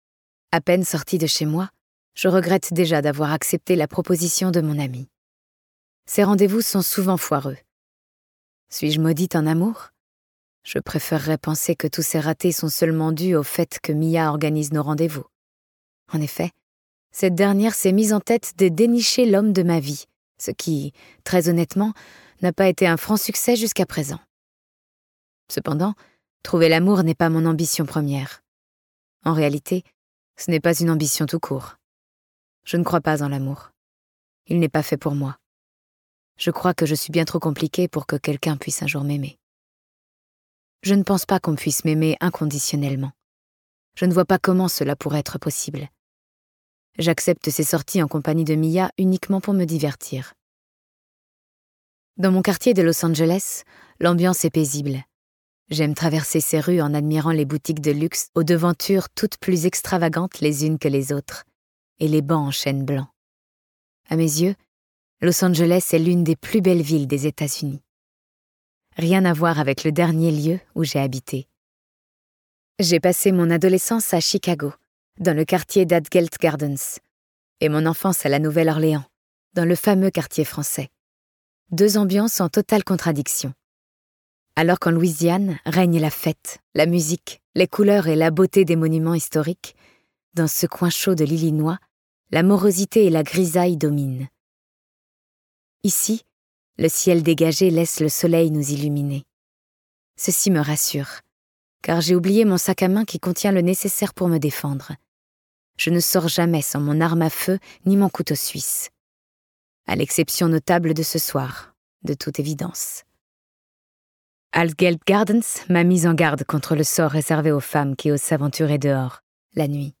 Une romance à haut risque incarnée par un duo de comédiens aux voix complémentaires et addictives. Lorsqu'un tueur cruel croise la route d'une étudiante au caractère bien trempé, ce sont deux univers diamétralement opposés qui s'entrechoquent... pour le meilleur et pour le pire.